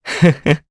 Crow-Vox_Happy2_jp_b.wav